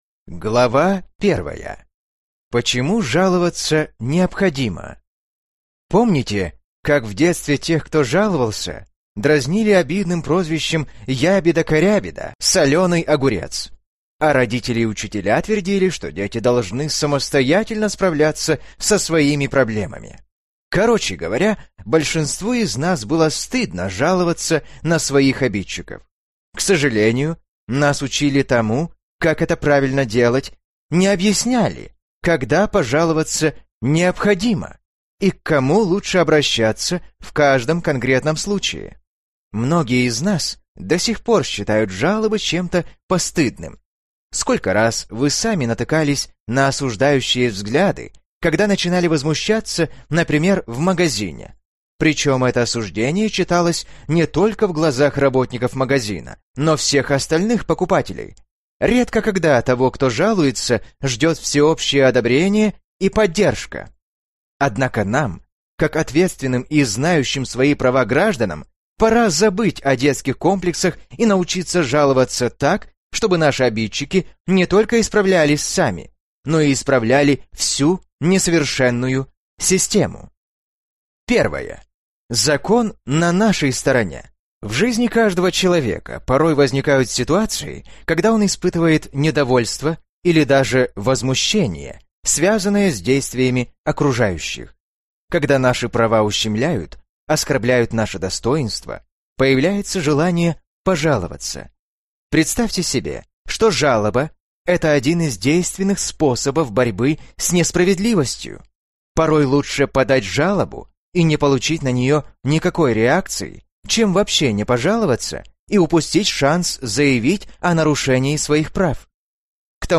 Аудиокнига Книга жалоб. Как добиться своего | Библиотека аудиокниг